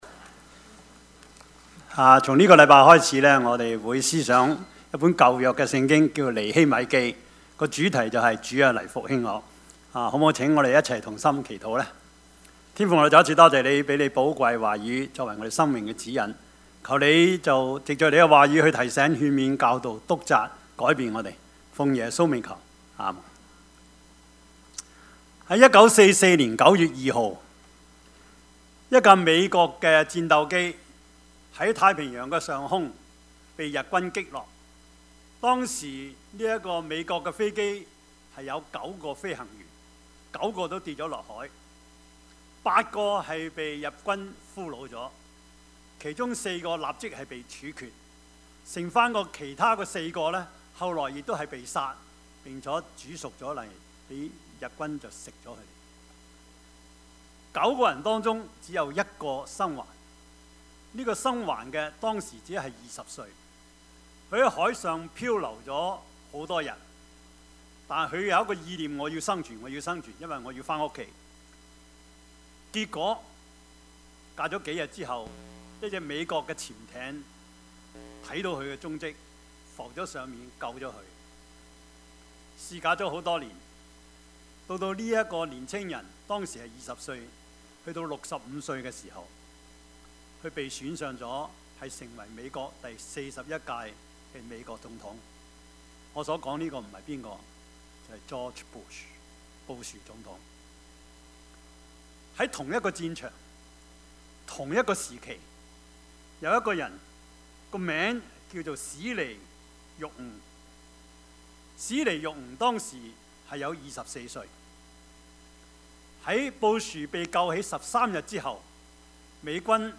Service Type: 主日崇拜
4 我 聽 見 這 話 ， 就 坐 下 哭 泣 ， 悲 哀 幾 日 ， 在 天 上 的 神 面 前 禁 食 祈 禱 ， 說 ： Topics: 主日證道 « 每週一字之「北」一 一個母親的禱告 »